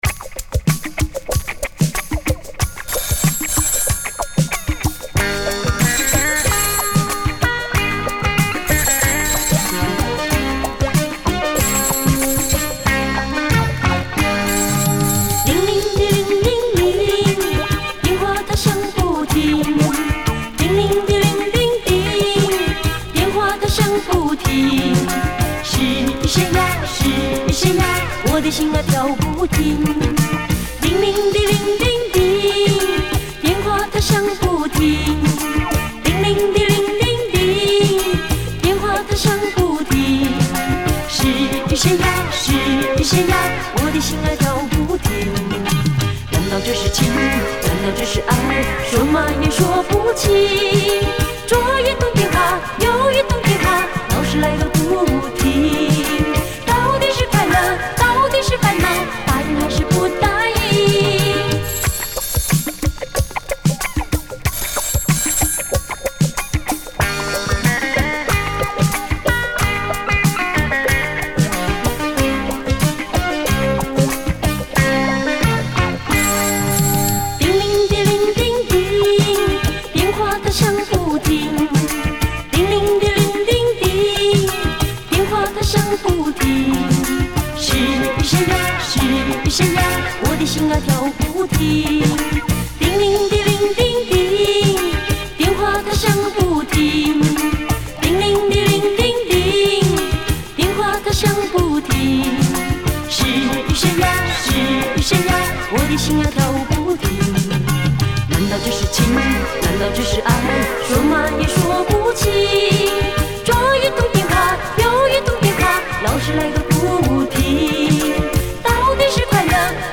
流行 .